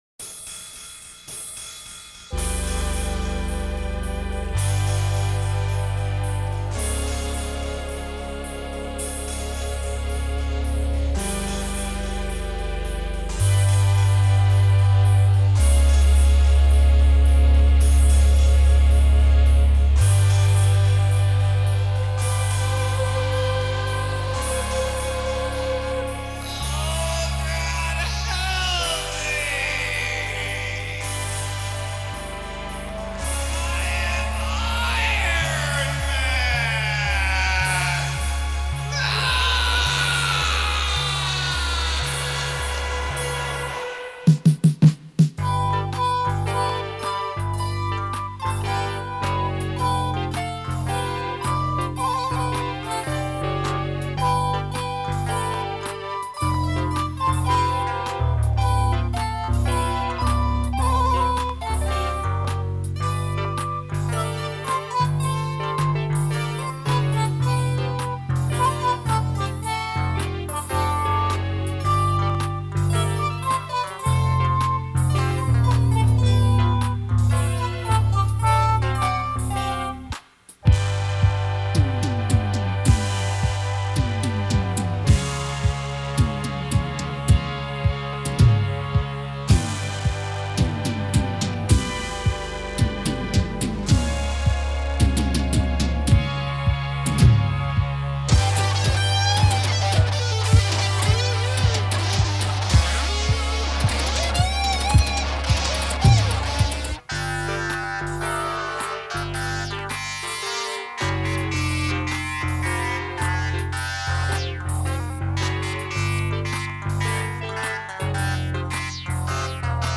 recorded in 1984. A medley of christmas tunes that are sure to ... ring your bells.